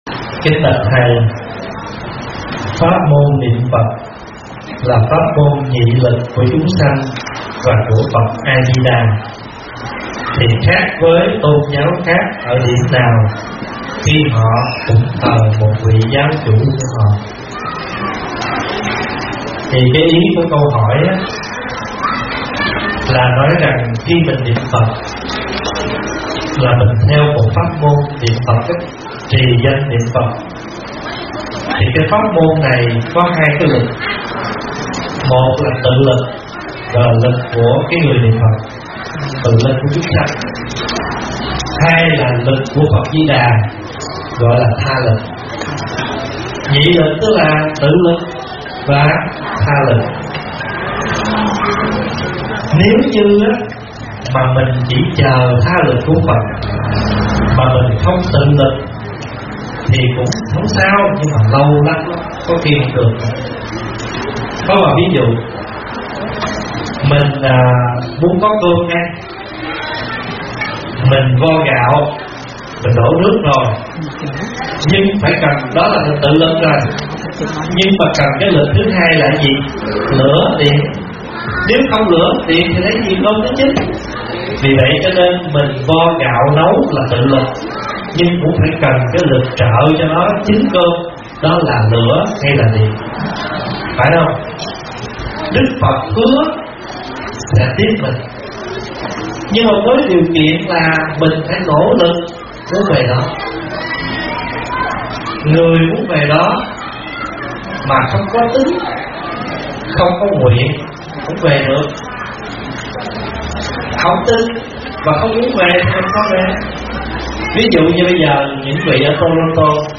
Nghe mp3 vấn đáp